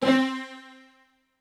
STR HIT C3.wav